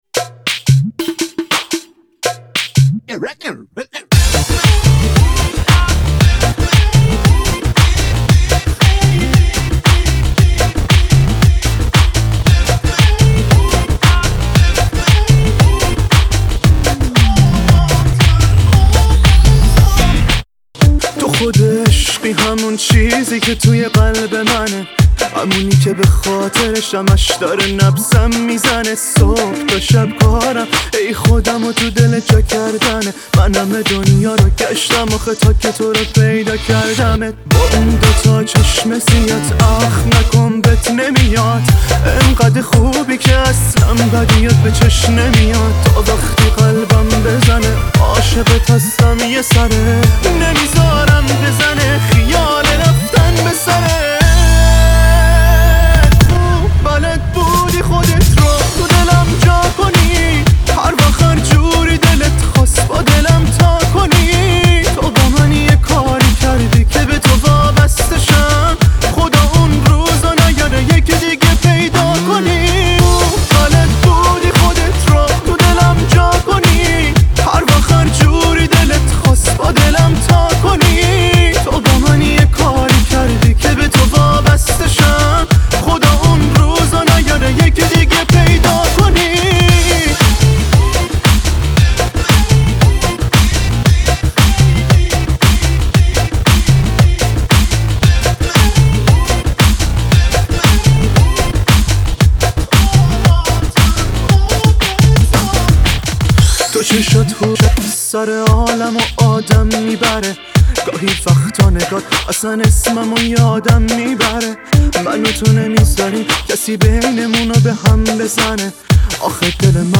خوبه شاده